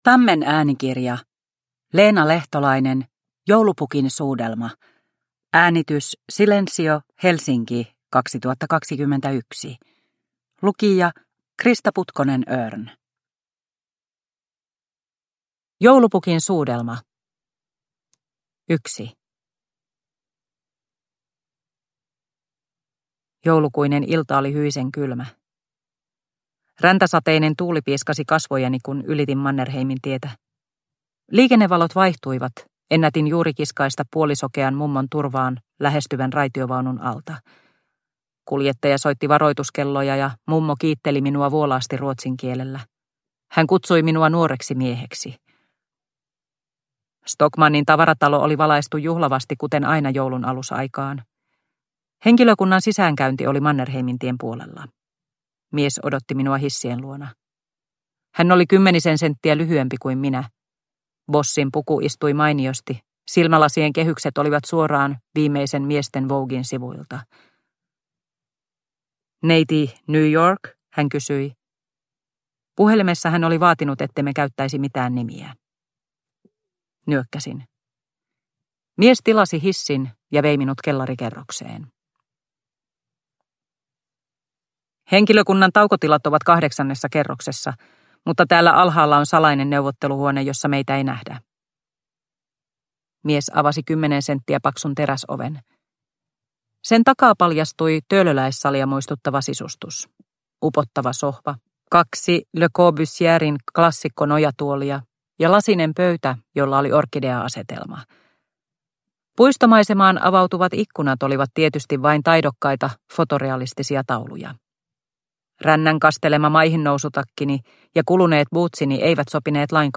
Joulupukin suudelma – Ljudbok – Laddas ner